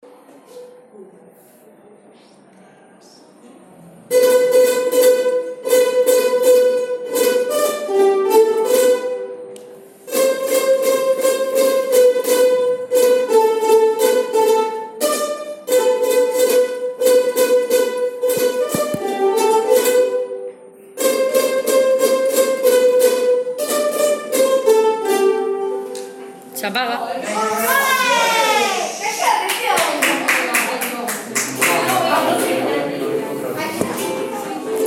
Nadala feta pels alumnes de 1r d’ESO amb ukeleles